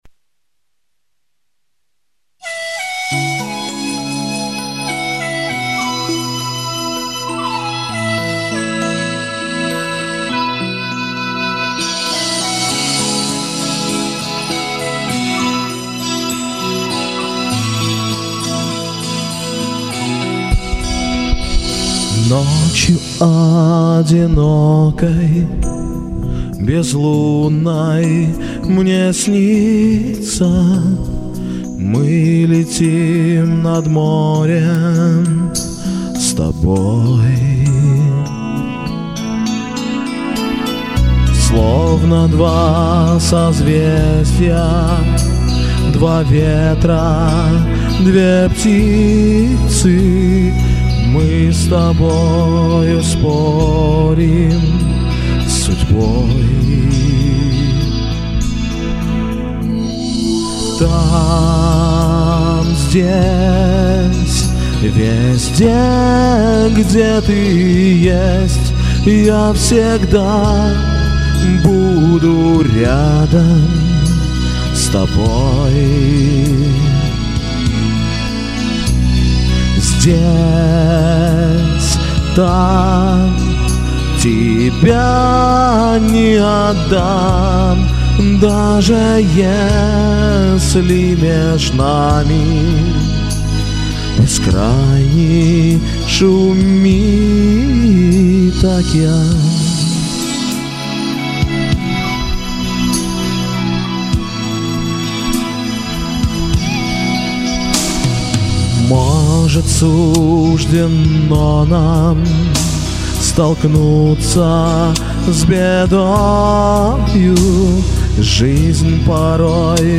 Красиво и уверенно!